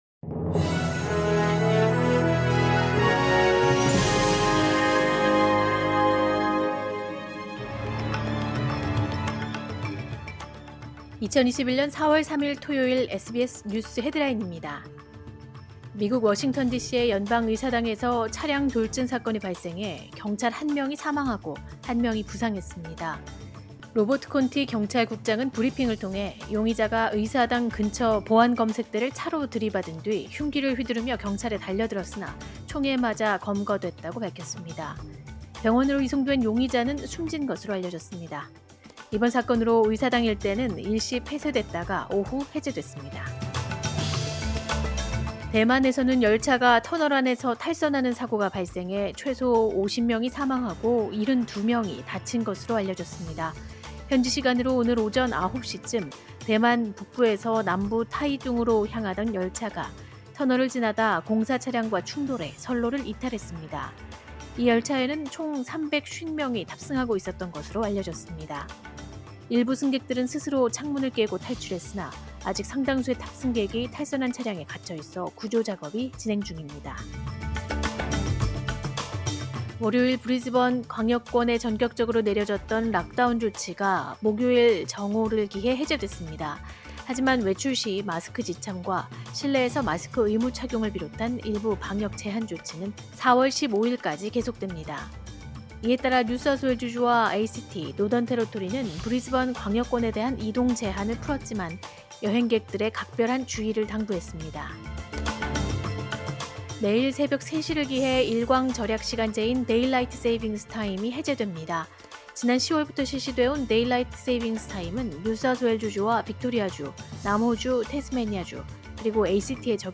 2021년 4월 3일 토요일 SBS 뉴스 헤드라인입니다.